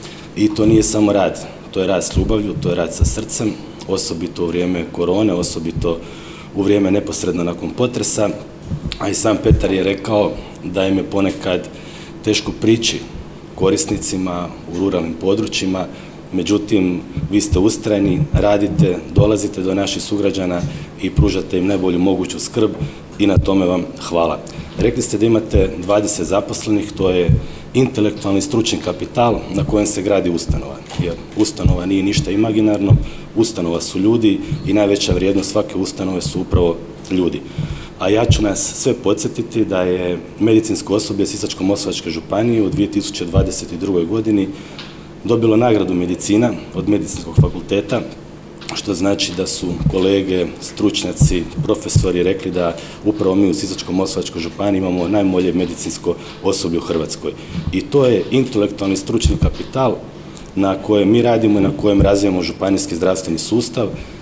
Prigodnom svečanosti u Sisku, pod pokroviteljstvom Sisačko-moslavačke županije i Hrvatske komore medicinskih sestara, zdravstvena ustanova Zrinus iz Petrinje obilježila je 20 godina rada.
Na predanom i kontinuiranom radu čestitao je i župan Sisačko-moslavačke županije Ivan Celjak: